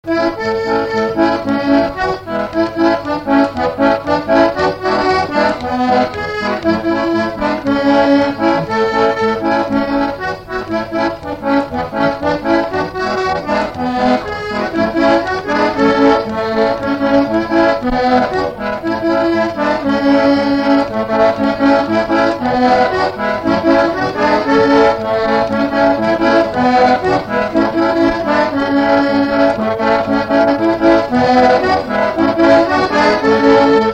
Saint-Louis
Instrumental
danse : séga
Pièce musicale inédite